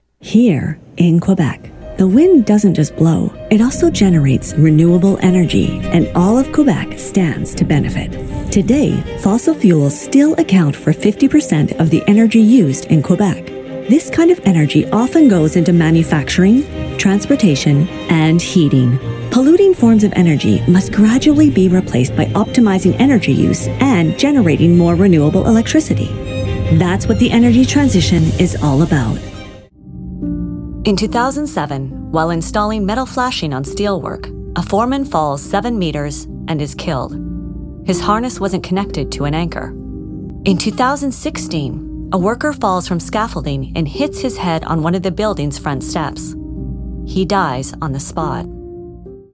Narration - ANG